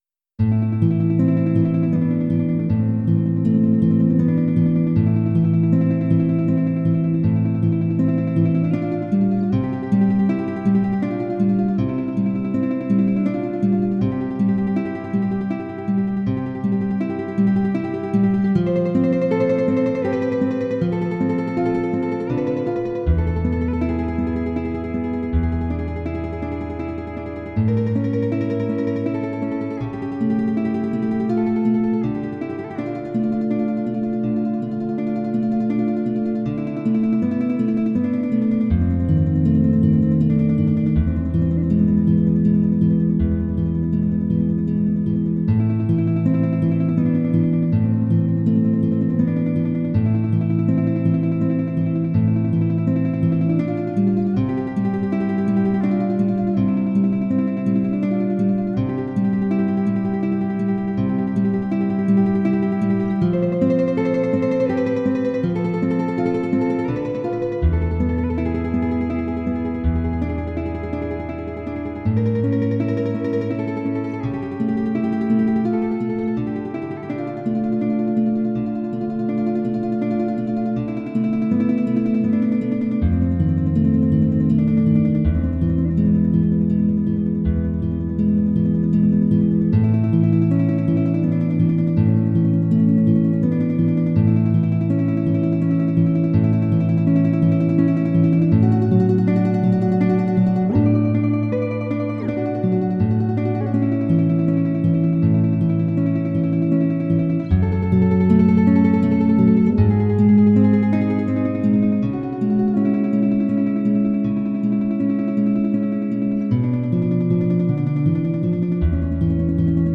�l�h�c�h�@�N���V�b�N�M�^�[�@�A���n���u���{�a�̎v���o (Recuerdos dela Alhambra)